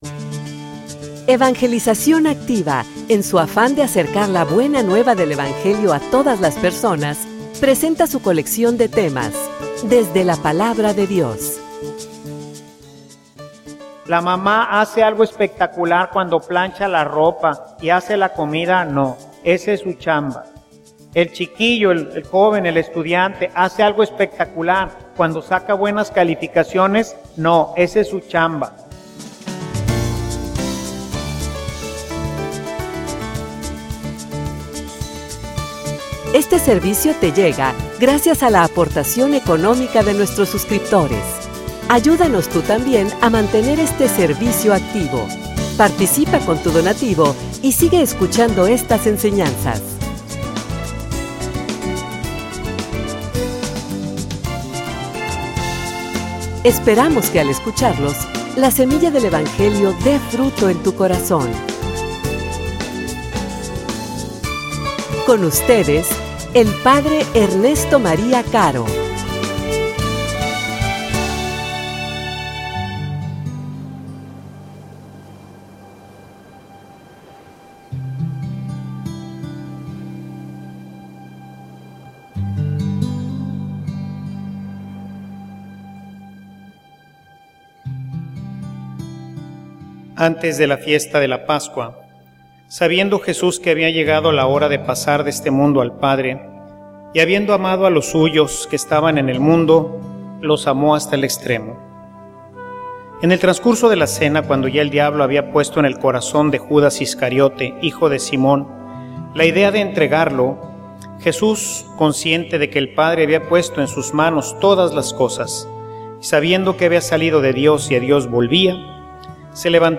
homilia_Amor_que_se_hace_generosidad.mp3